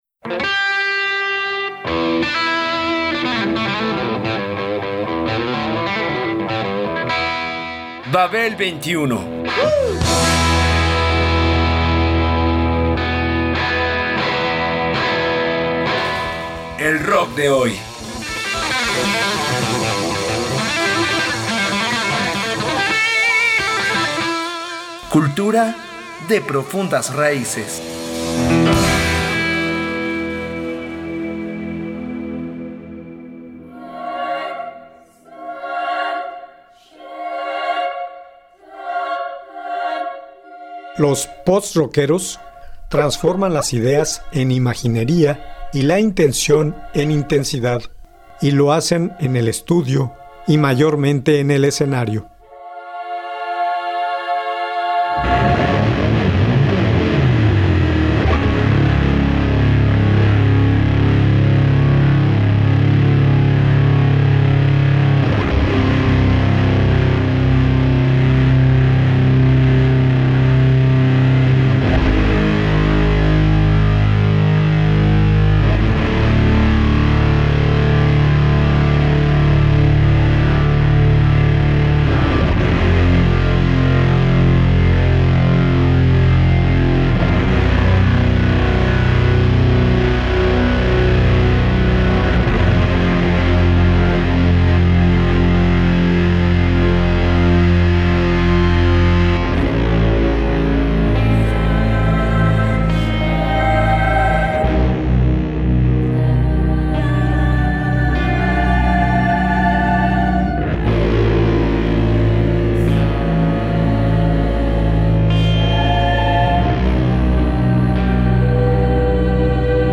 Un estilo de música minimalista, básicamente instrumental y donde la voz es un elemento sonoro más, que se caracteriza por el uso de sonidos, notas sostenidas o repetidas en el tiempo. A este tipo de sonido se le denomina drone .